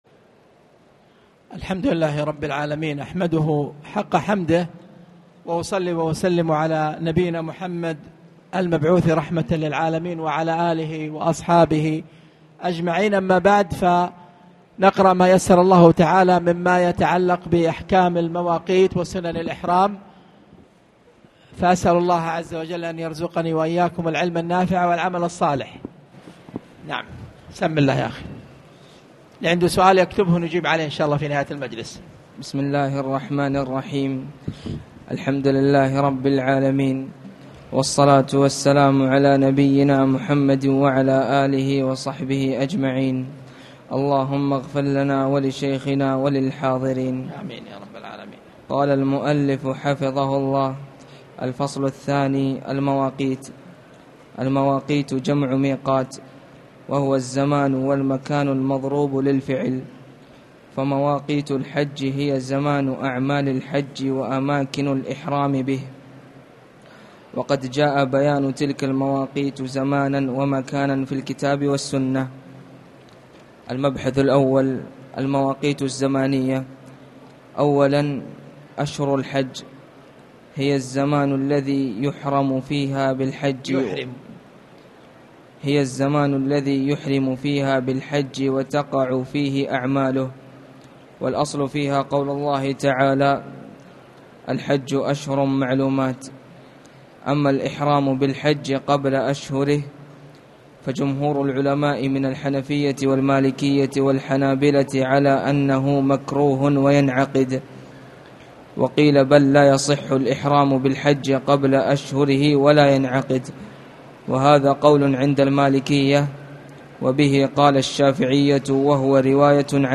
تاريخ النشر ٢٧ شوال ١٤٣٨ هـ المكان: المسجد الحرام الشيخ